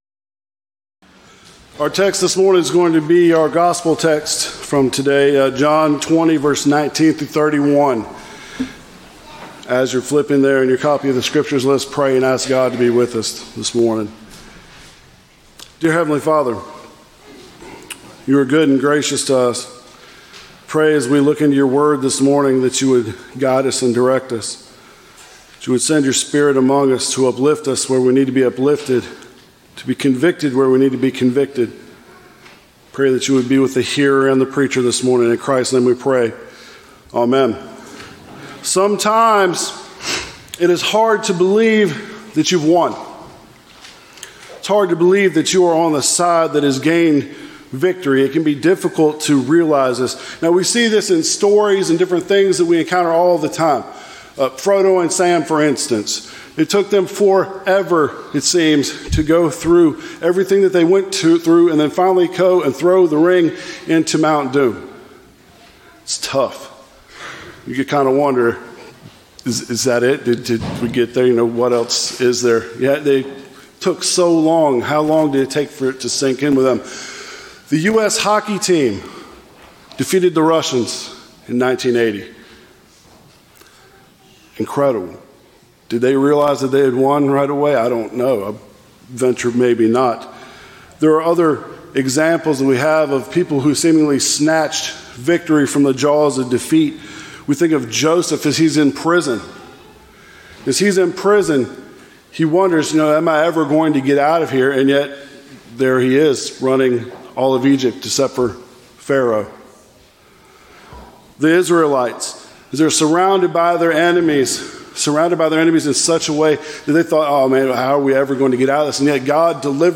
from April 12, 2026 (The Second Sunday of Easter)